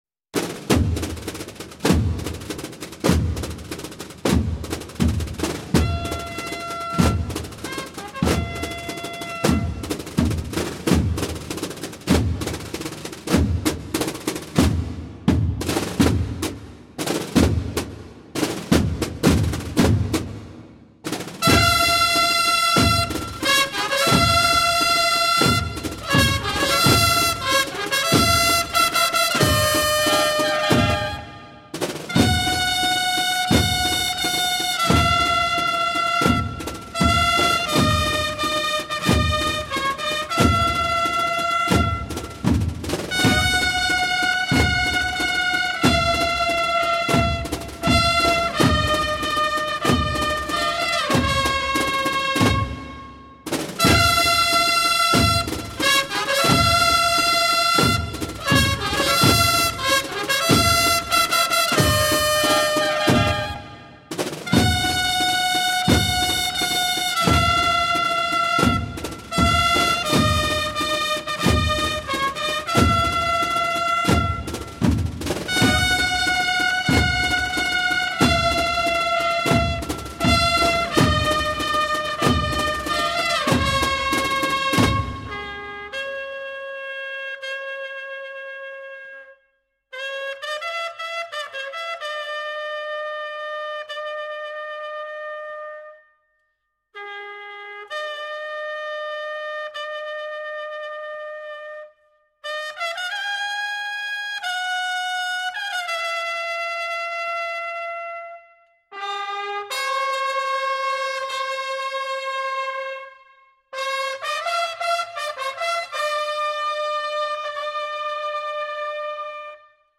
Música Nazarena
Pulsa aqui para escuhar este audio "Caridad" - La "Posá" Banda de Cornetas y Tambores Hermandad de Ntra.
Está integrada por 60 componentes que visten: pantalón negro, camisa granate, boina negra, galones azules, emblemas bordados en galas y calzado negro.